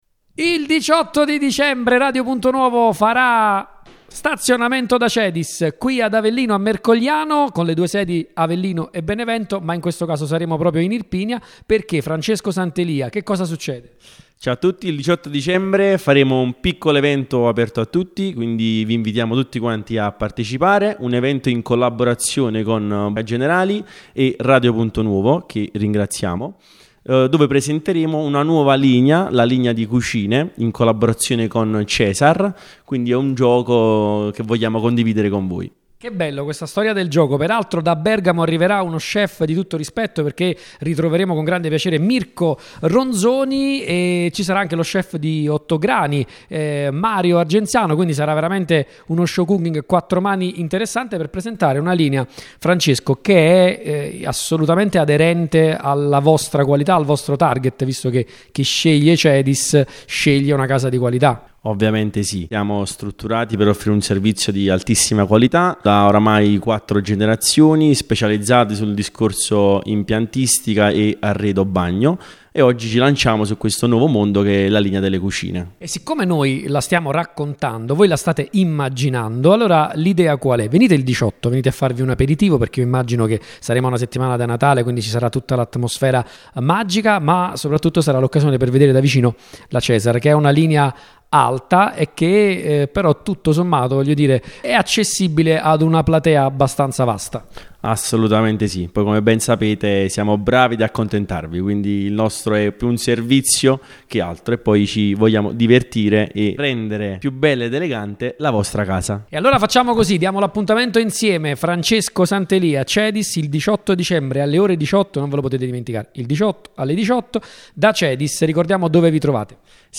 CEDIS-Intervista.mp3